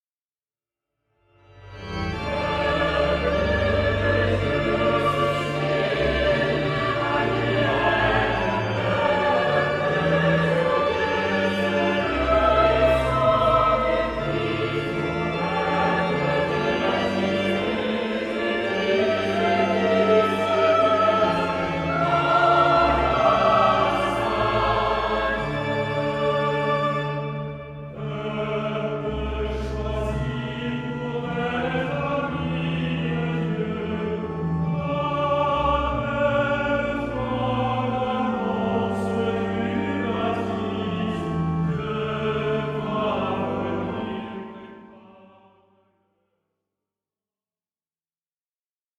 Harmonisations originales de chants d'assemblée